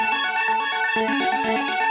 Index of /musicradar/shimmer-and-sparkle-samples/125bpm
SaS_Arp02_125-A.wav